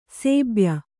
♪ sēbya